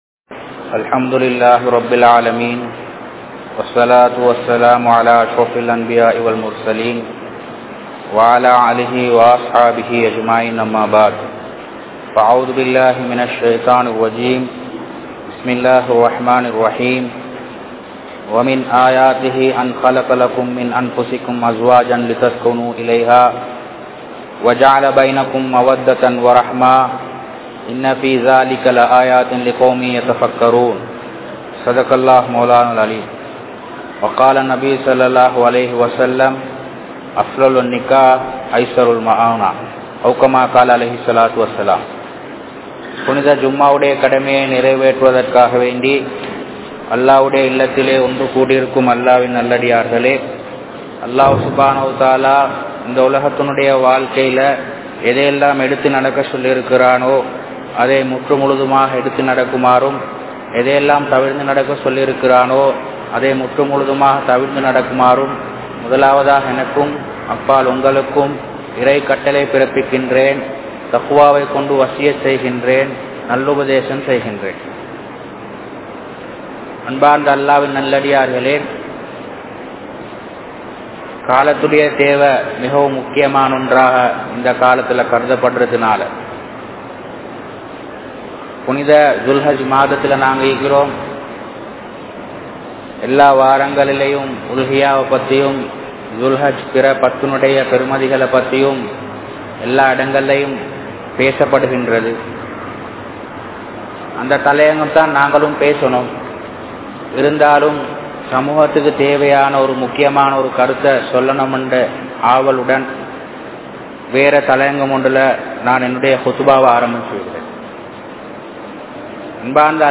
Muslimkal Tholaiththa Islamiya Kalaachaaram (முஸ்லிம்கள் தொலைத்த இஸ்லாமிய கலாசாரம்) | Audio Bayans | All Ceylon Muslim Youth Community | Addalaichenai
Grand Jumua Masjitth